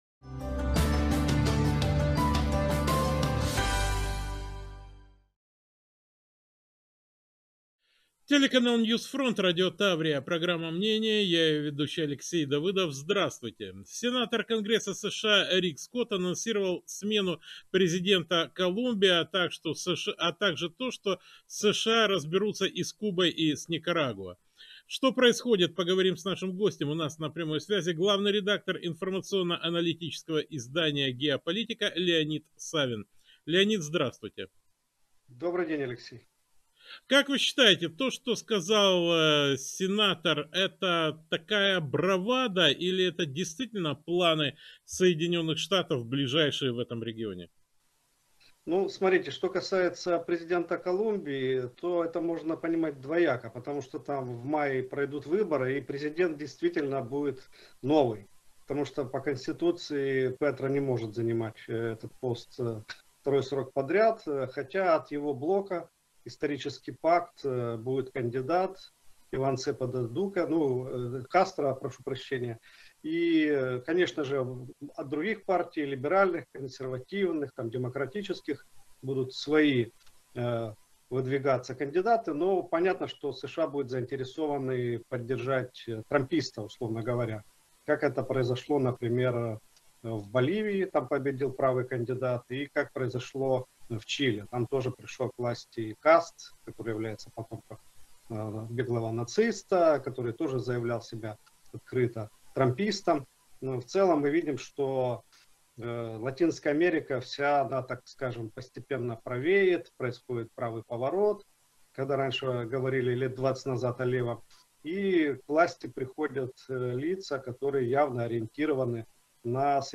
Собеседник ведущего выразил мнение, что операция в Венесуэле была "пробной" и после этого правительство США будет обдумывать последующие шаги по расширению влияния в регионе.